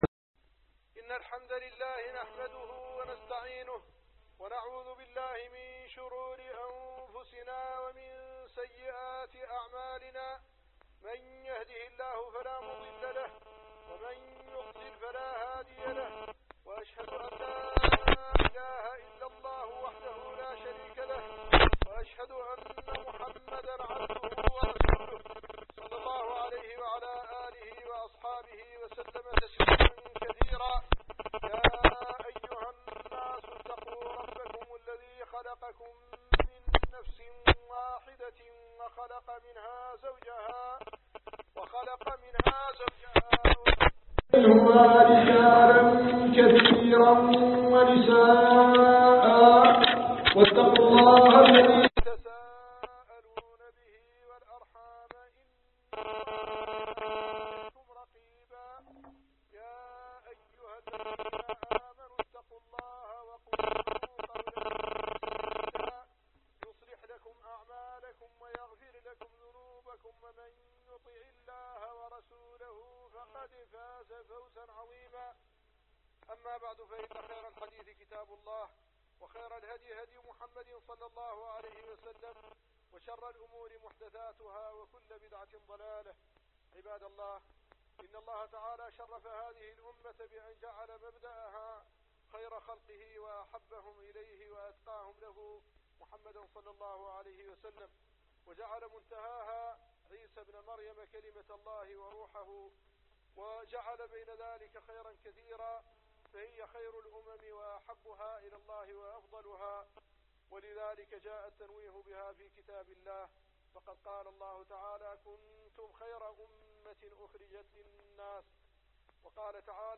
كنتم خير أمة أخرجت للناس - خطب الجمعة